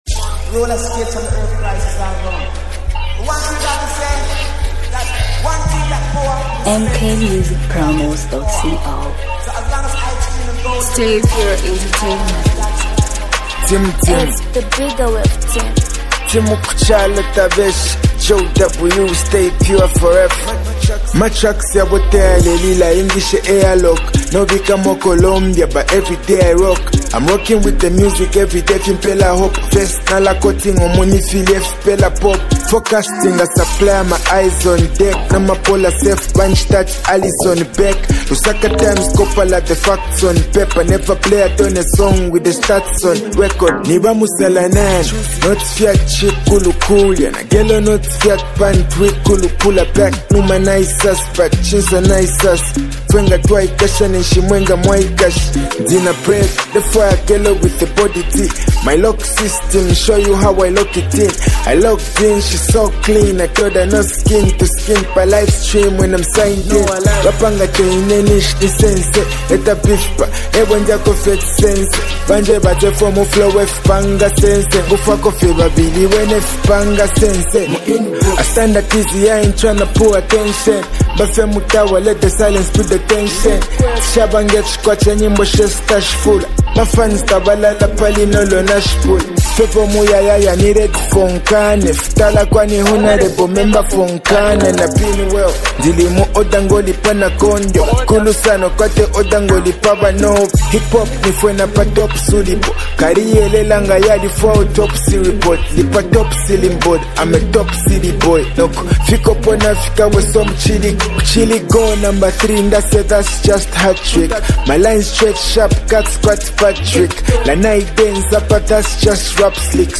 hard-hitting Zambian hip-hop release
pure lyrical aggression, confidence, and technical rap skill